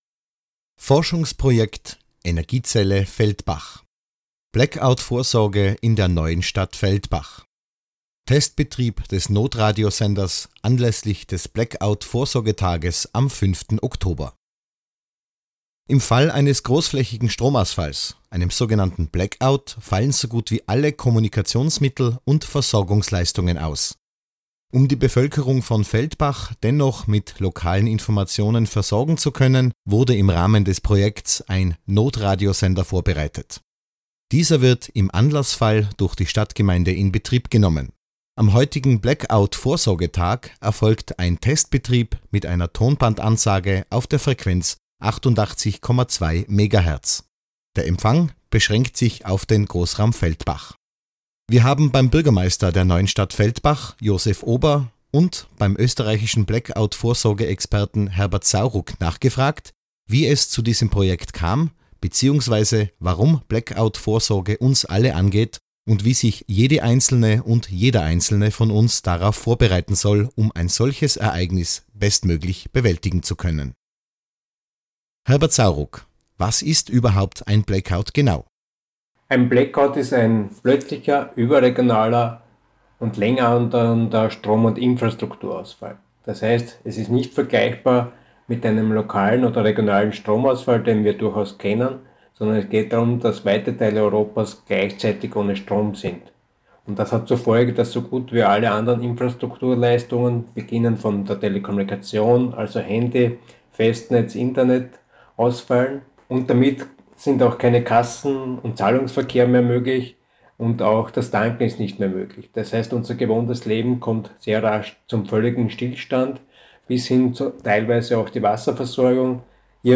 von Testausstrahlung am 05.10.19
notradiosender-feldbach-5.10.19-interviews.mp3